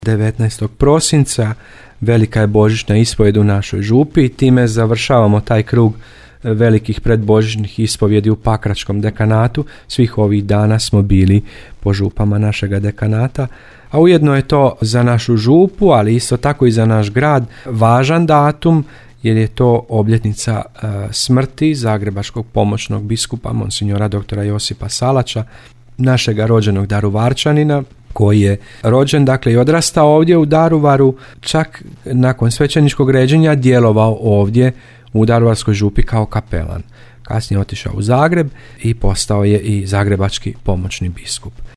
Današnji datum važan je za Župu Presvetog Trojstva i za Grad Daruvar.